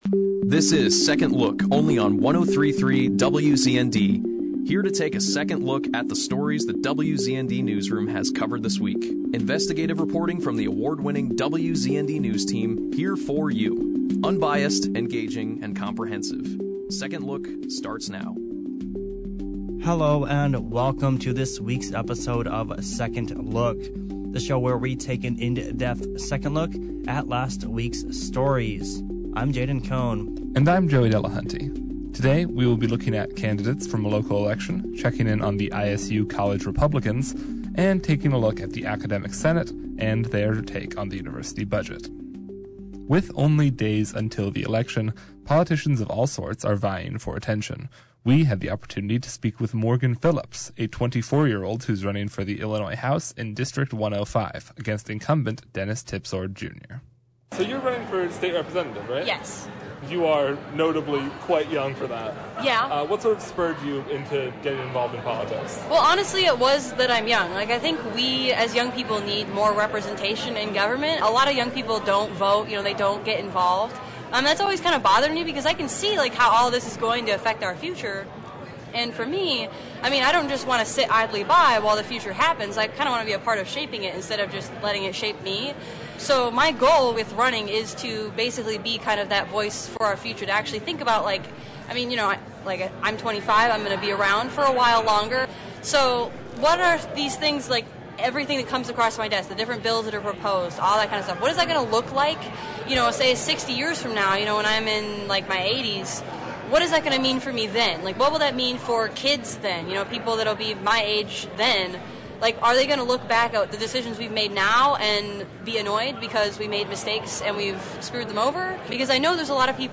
The show opened with an interview